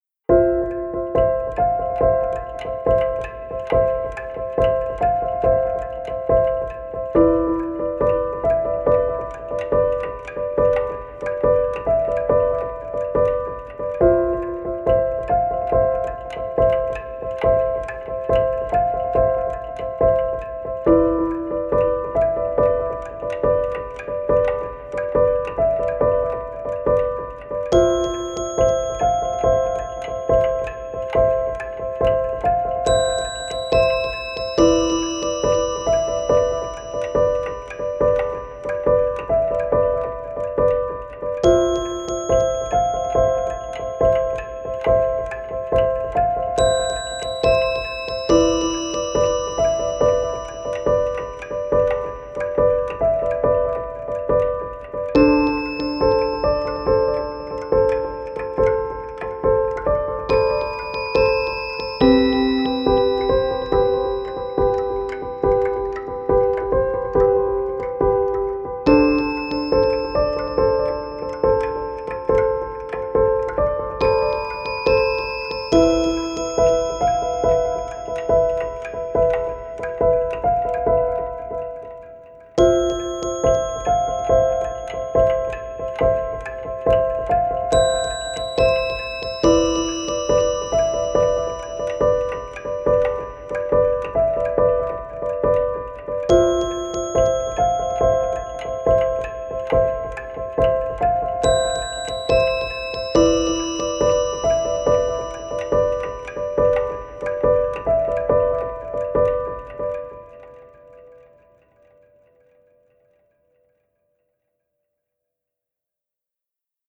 テンポ：♩=70
主な使用楽器：ピアノ、シンセベル、パーカッション etc